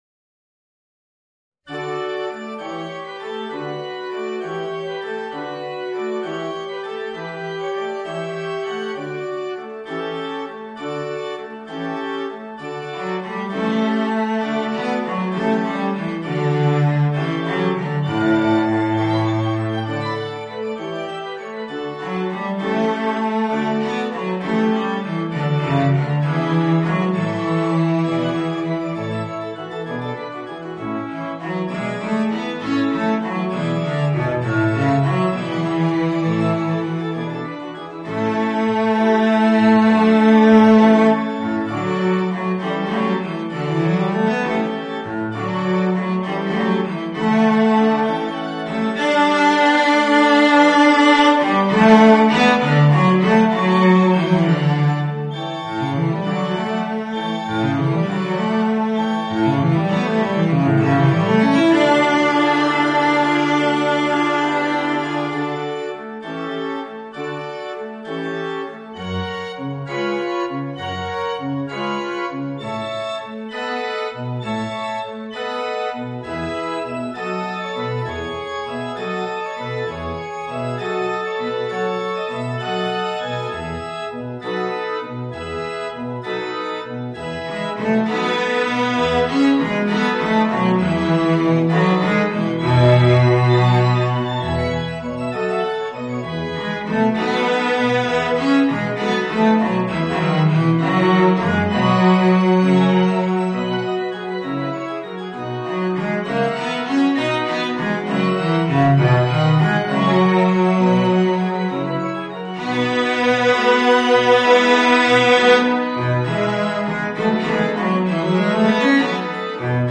Voicing: Violoncello and Organ